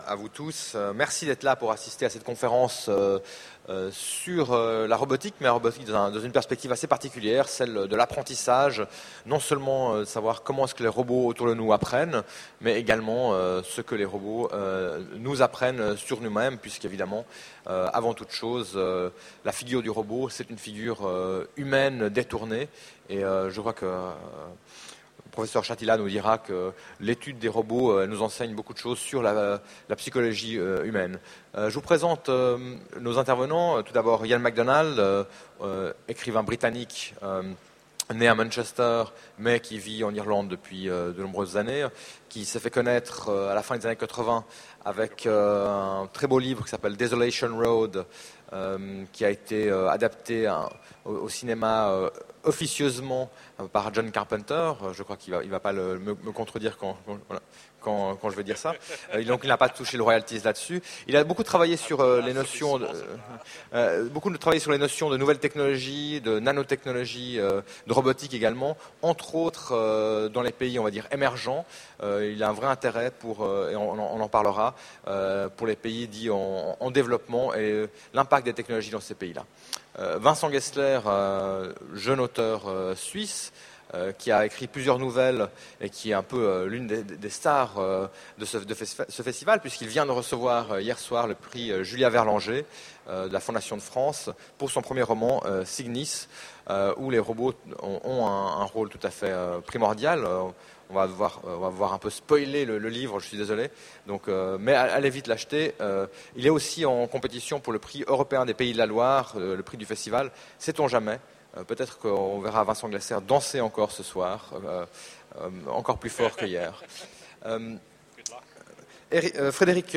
Utopiales 2010 : Conférence Je suis un robot et je vous apprends